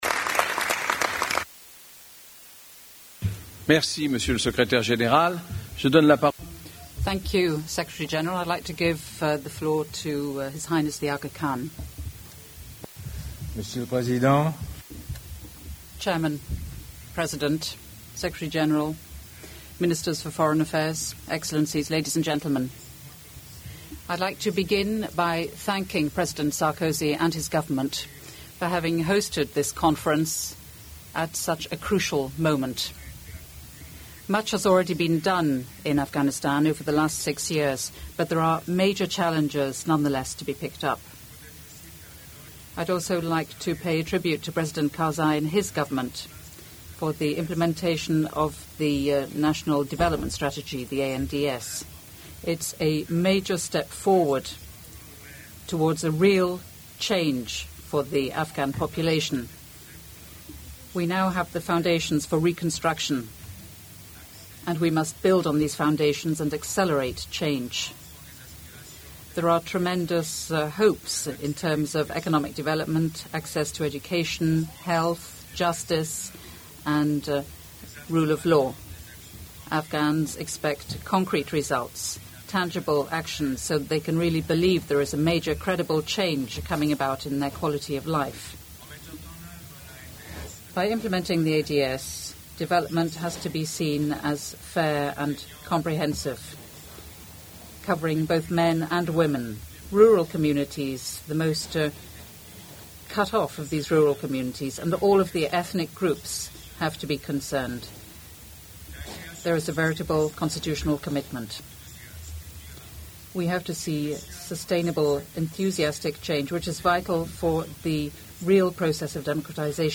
Audio translation of speech given by His Highness the Aga Khan